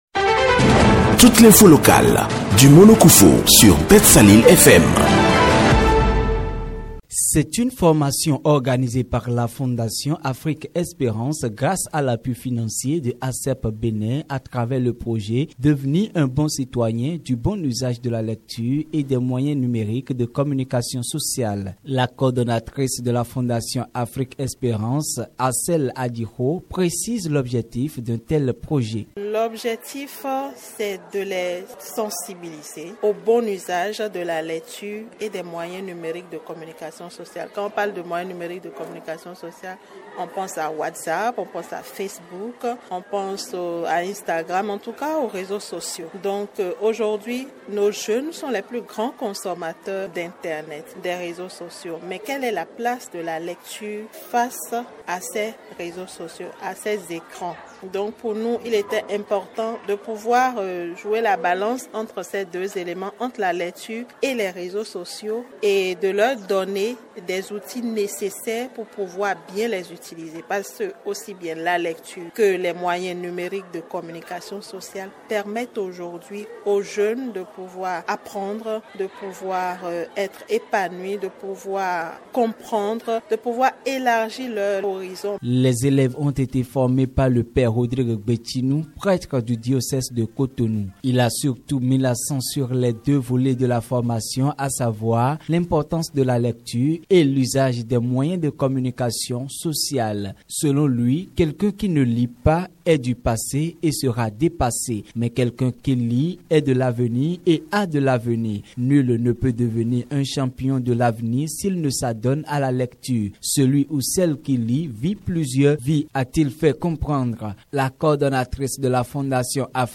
Les élèves du Collège d’enseignement général de Houéyogbé en savent davantage sur l’importance de la lecture et l’usage des réseaux sociaux. Ceci grâce à une formation organisée par la Fondation Afrique Espérance. L’activité a eu lieu ce ce mercredi 22 janvier 2025 dans l’enceinte du collège en présence des autorités administratives.